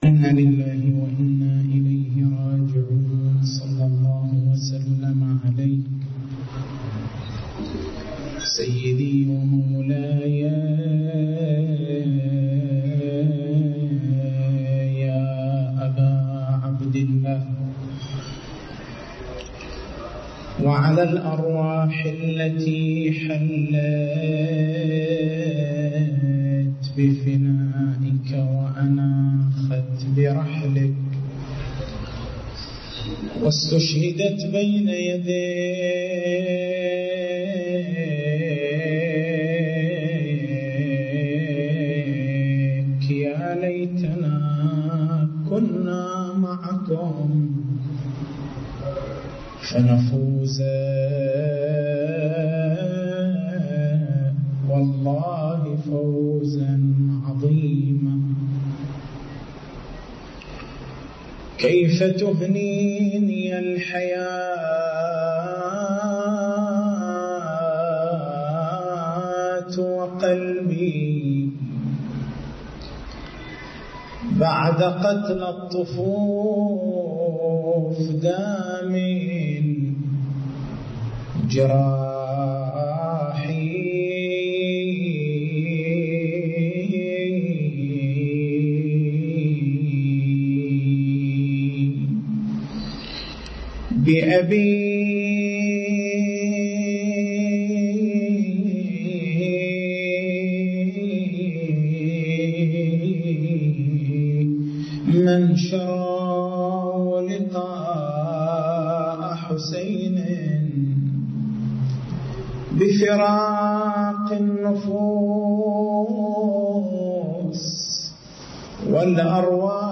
تاريخ المحاضرة: 03/09/1431 محور البحث: كيف يصحّ للداعي أن يقول في شهر رمضان: ((اللهم ارزقني حجّ بيتك الحرام في عامّي هذا وفي كلّ عام)) مع أنه قد لا يكون راغبًا في الحجّ كلّ عام، والحال أن من شروط الدعاء العزم والنيّة؟